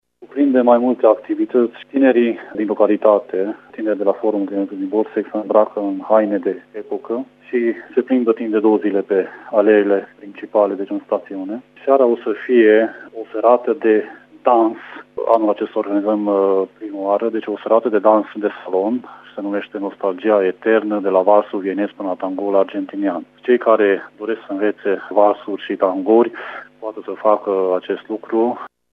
Salonul de dansuri de societate în aer liber este una din cele mai așteptate atracții din programul zilei de mâine, spune primarul orașului Borsec, Mik Jozsef: